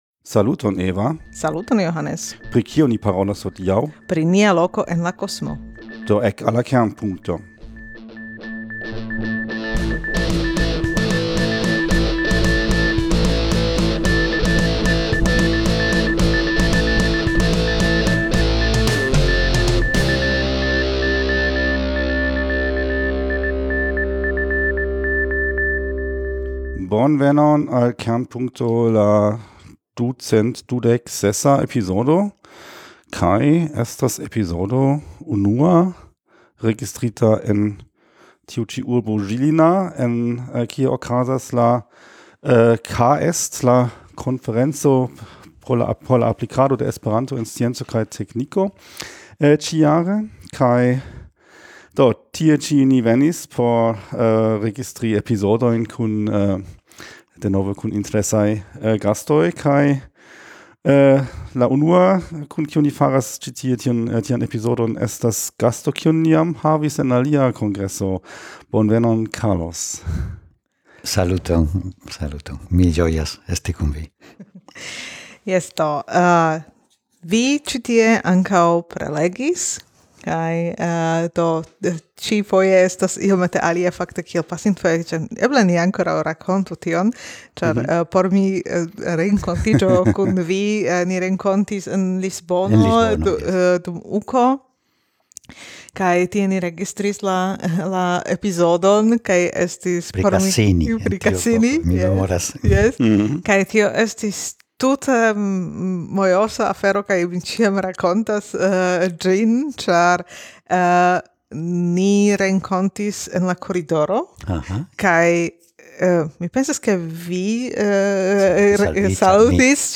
Interparolo pri la paŝoj kompreni kio estas nia loko en la universo